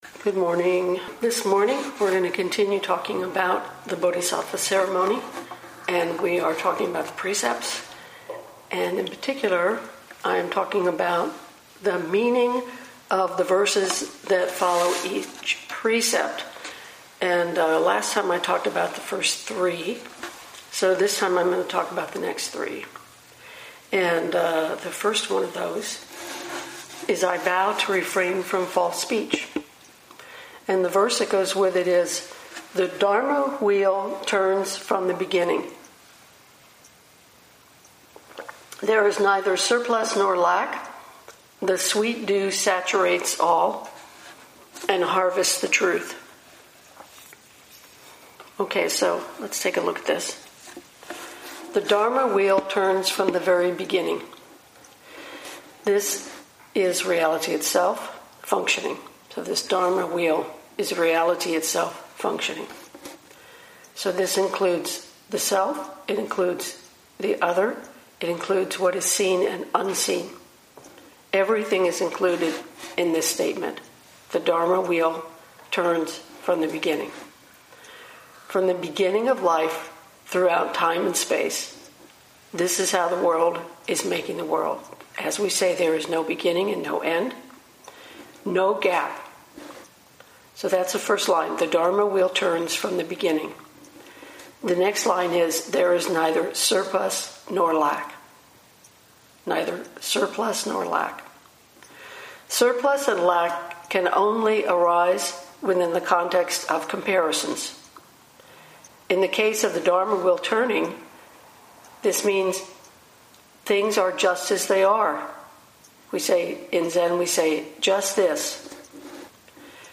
2021 in Dharma Talks